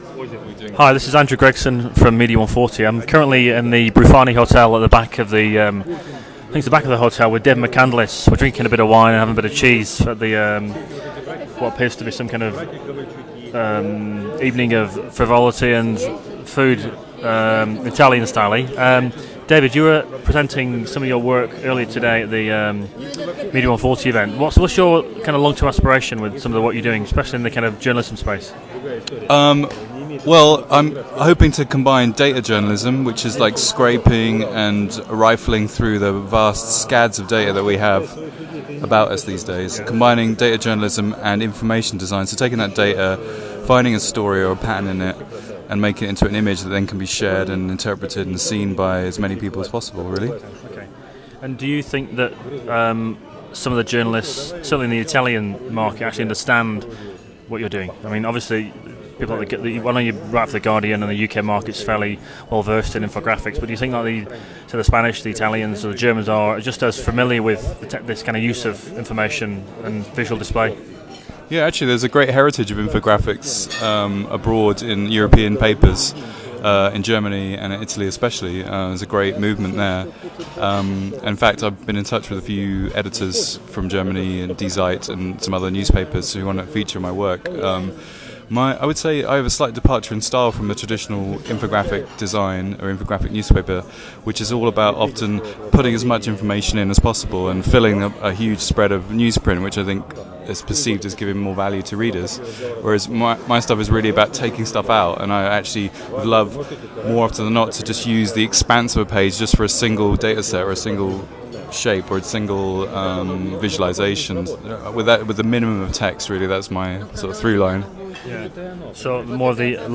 Interview with David McCandless